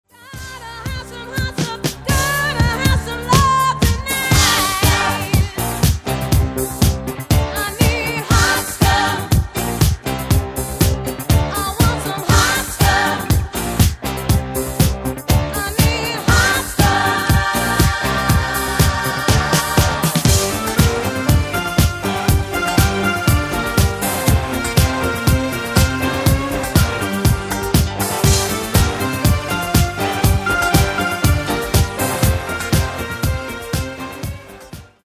Genere:   Disco | Soul